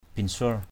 /bin-sʊor/ (d.) tên một vị vua Champa. name of a Champa king.